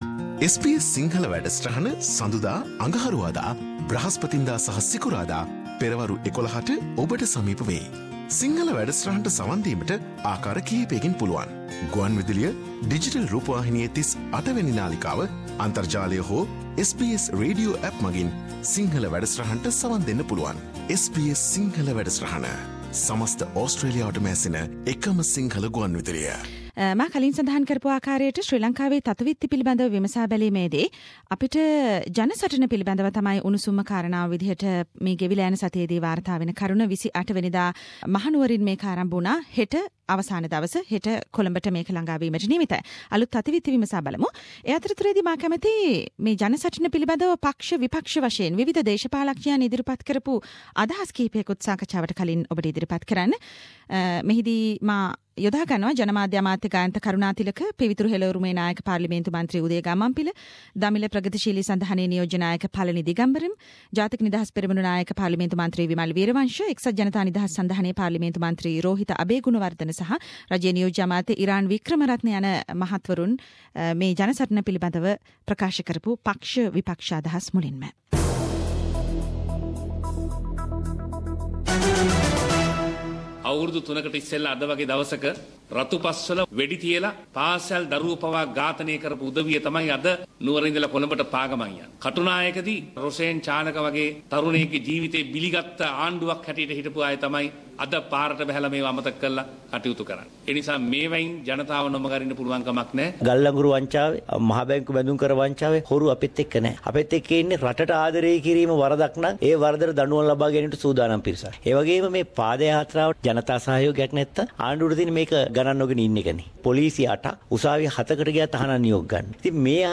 Weekly Sri Lankan news wrap – Inside stories of Joint opposition’s “ Jana Satana” pada Yathra